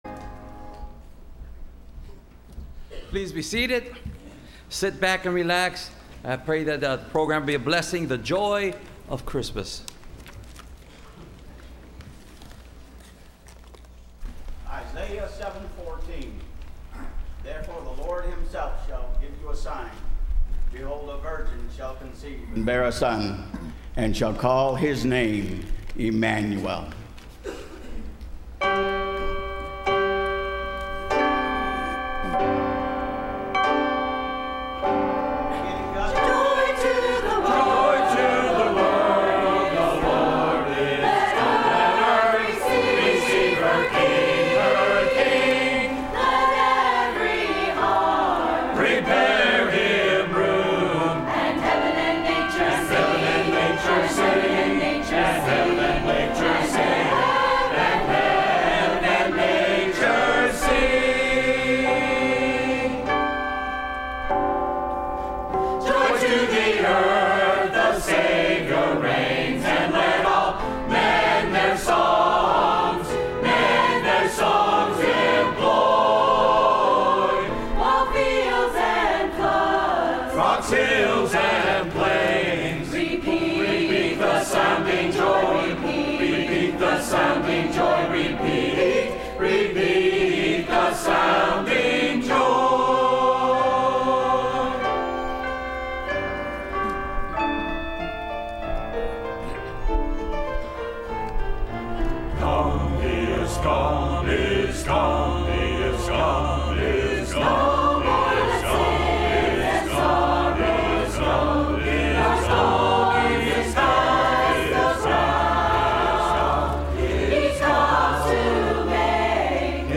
Christmas Cantata – Landmark Baptist Church
Service Type: Sunday Evening